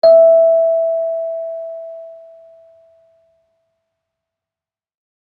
kalimba1_circleskin-E4-mf.wav